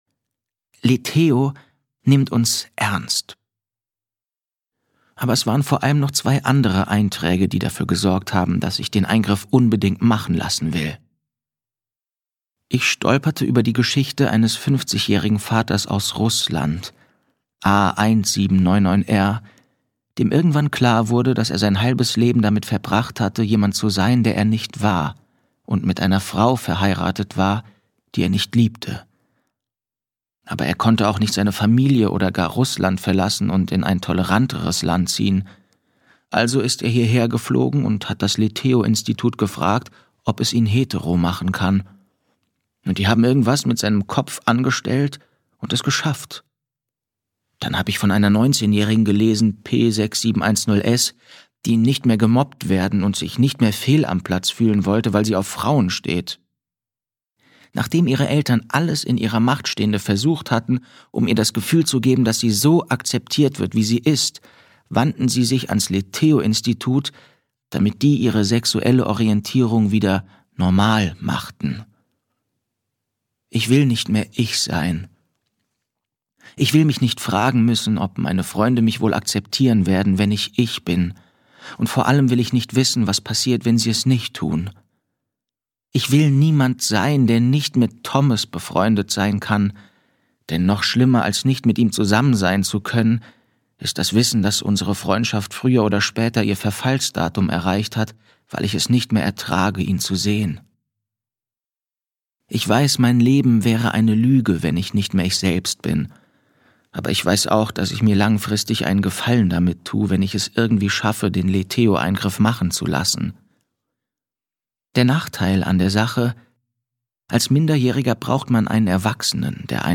2022 | 1. Auflage, Gekürzte Ausgabe, Autorisierte Hörbuchfassung